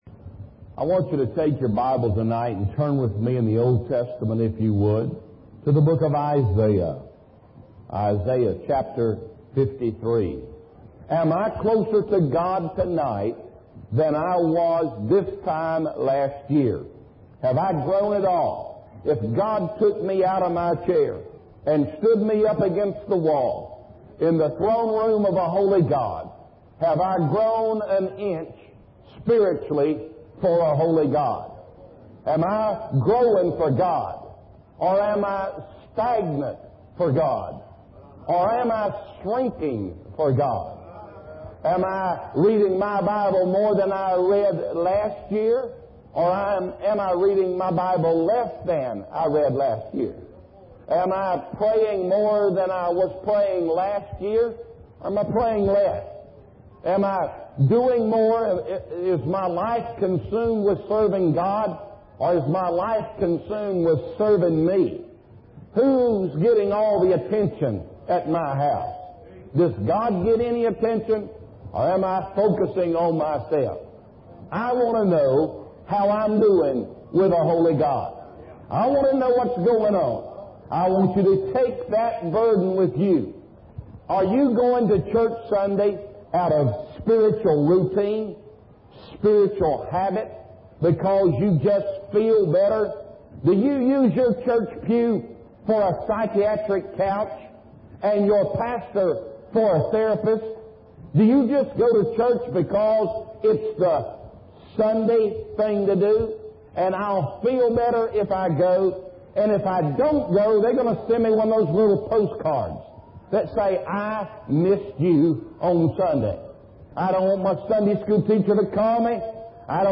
In this sermon, the preacher emphasizes the importance of being prepared for the imminent return of Jesus. He reminds the congregation that their lives are not their own, but belong to God.